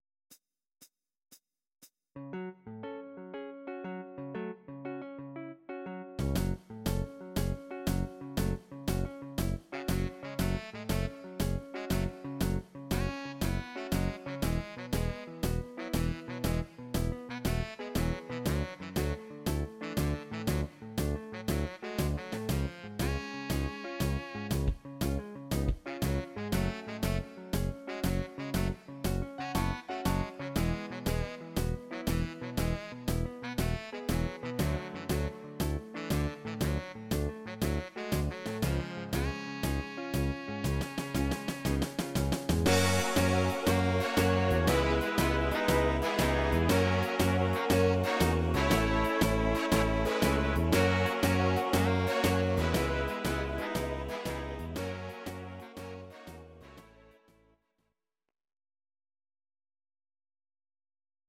Audio Recordings based on Midi-files
Pop, Rock, Oldies, 1960s